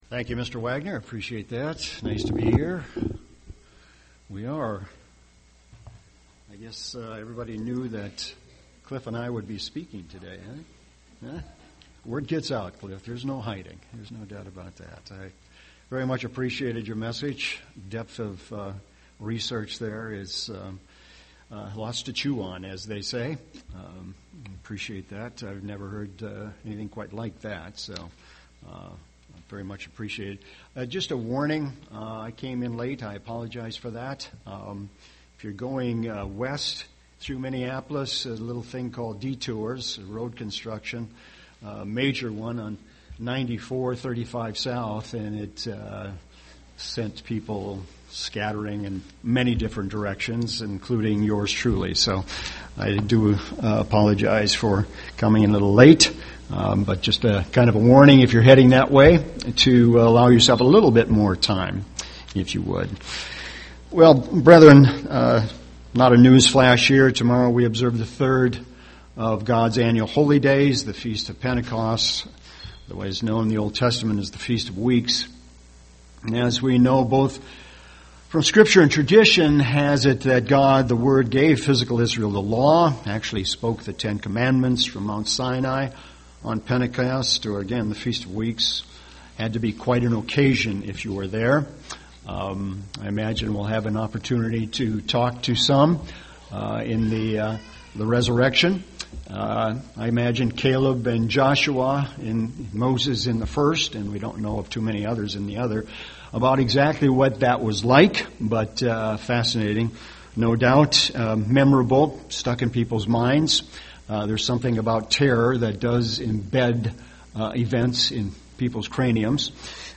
UCG Sermon Godly wisdom Studying the bible?